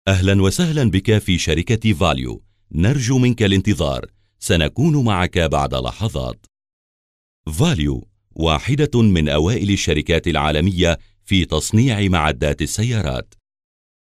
arabischer Sprecher.
Kein Dialekt
Sprechprobe: eLearning (Muttersprache):
voice over talent arabic.